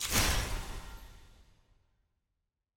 sfx-eog-ui-master-burst.ogg